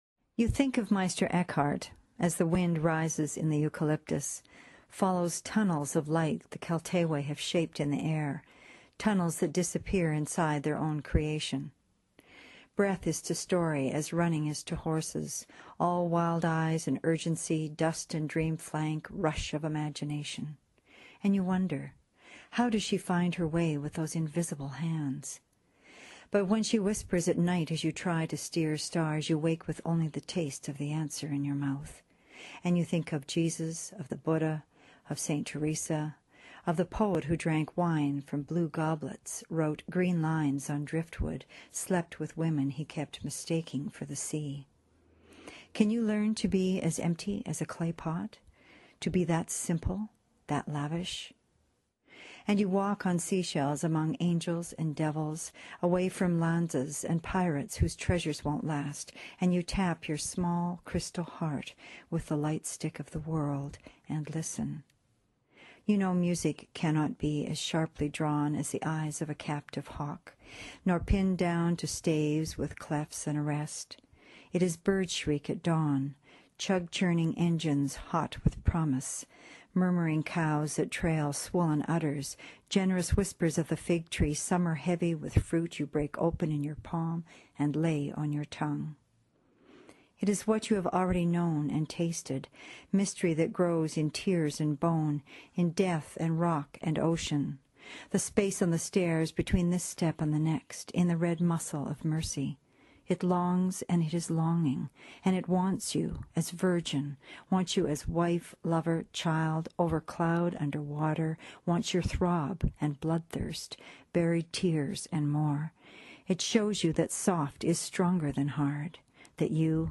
reads You Think of Meister Eckhart from Lost Gospels